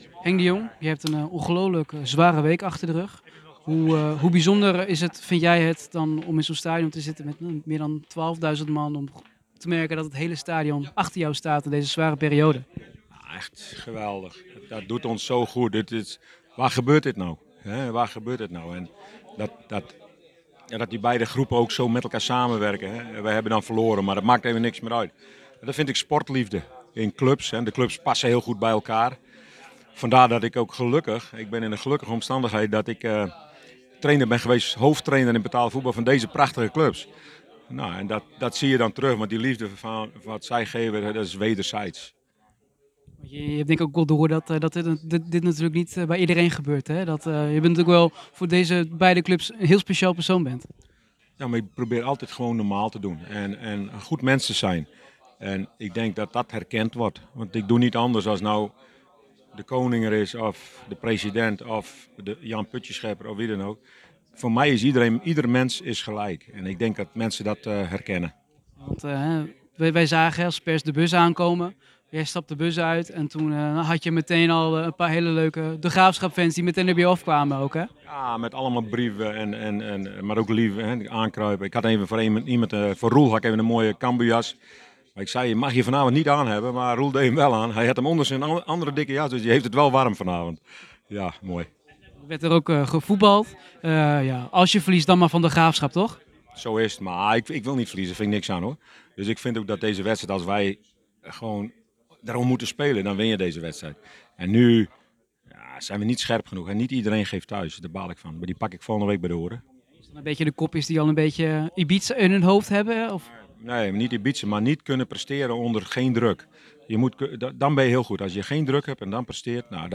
De trainer reageerde na de wedstrijd op deze eerbetonen, en de verloren wedstrijd.